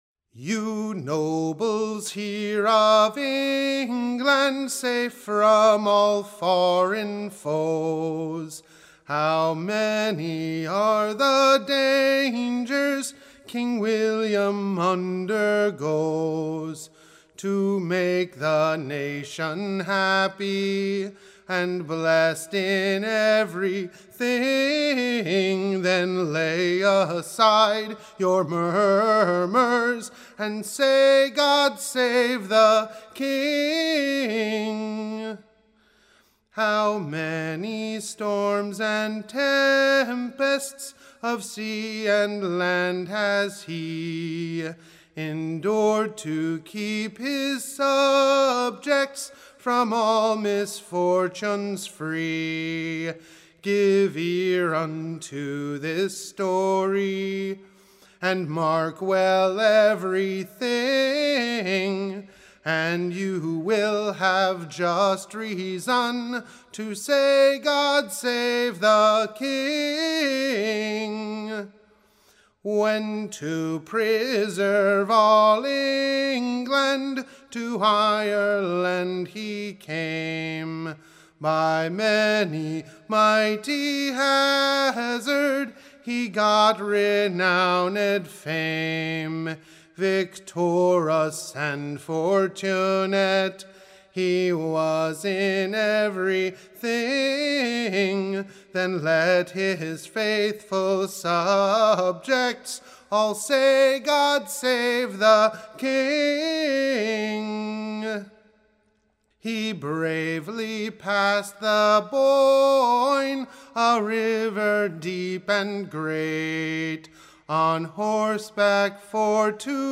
Recording Information Ballad Title Englands VVelcome to King William: / OR, THE / Loyal Subjects hearty Joy / For the Return of King VVILLIAM, after his many Perills / and Dangers.